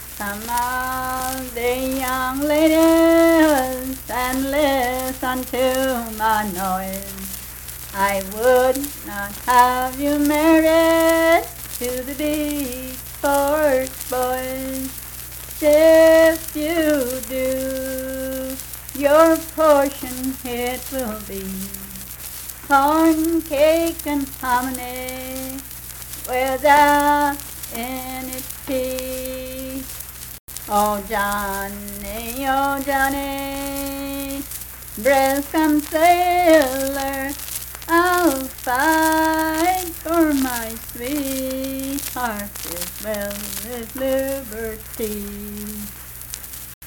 Unaccompanied vocal music
Verse-refrain 1(8).
Performed in Big Creek, Logan County, WV.
Voice (sung)